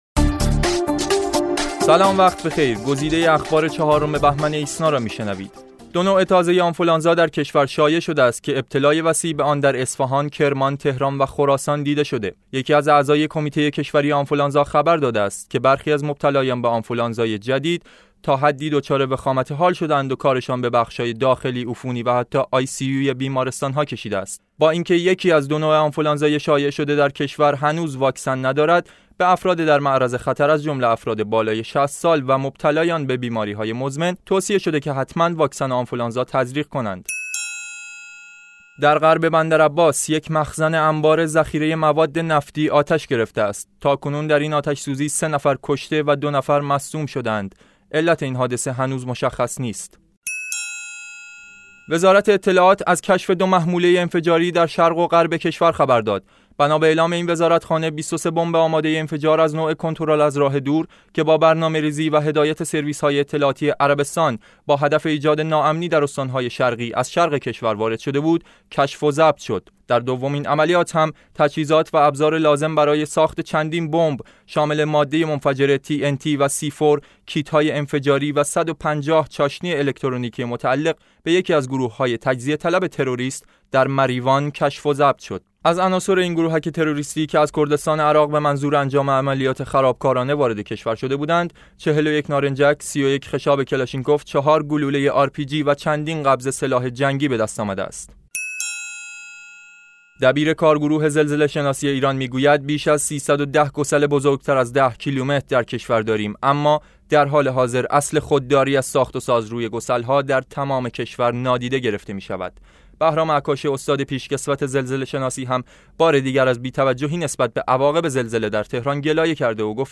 صوت / بسته خبری ۴ بهمن ۹۶